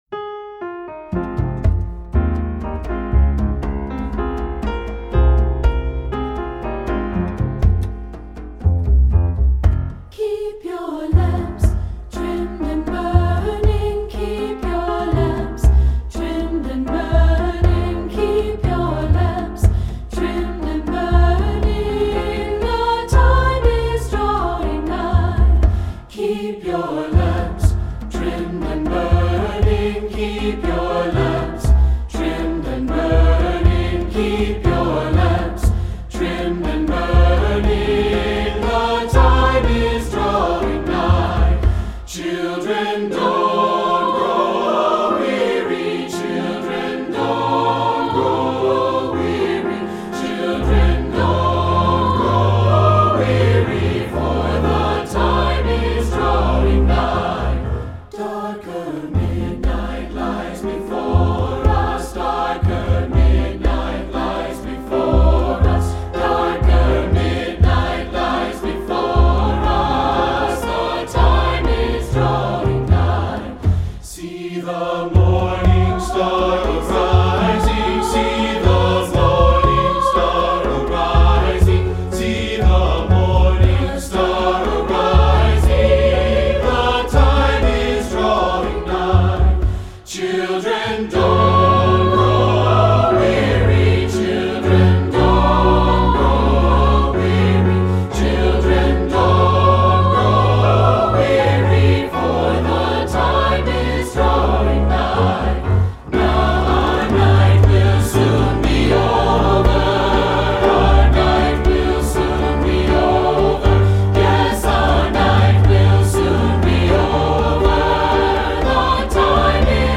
Composer: Spiritual
Voicing: SATB and Piano